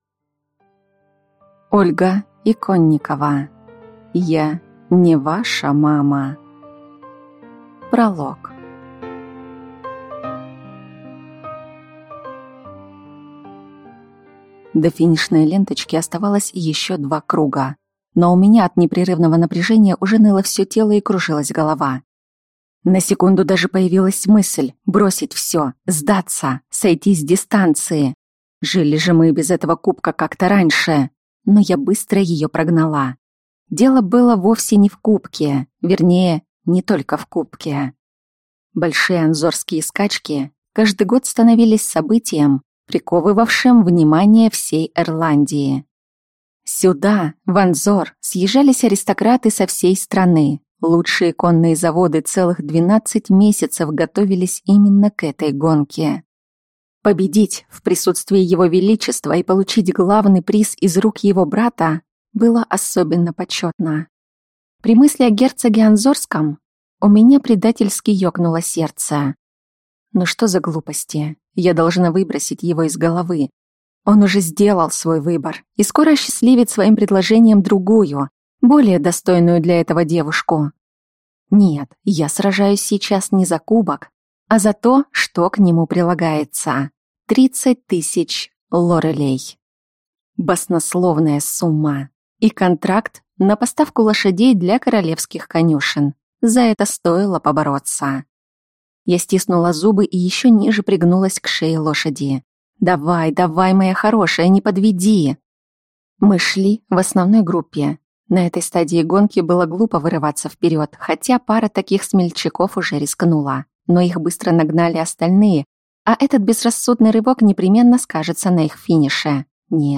Аудиокнига Я (не) ваша мама | Библиотека аудиокниг
Прослушать и бесплатно скачать фрагмент аудиокниги